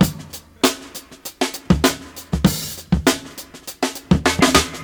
99 Bpm Drum Loop Sample G Key.wav
Free breakbeat sample - kick tuned to the G note.
99-bpm-drum-loop-sample-g-key-sUK.ogg